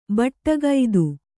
♪ baṭṭagaidu